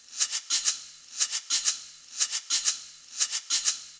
120_shaker_1.wav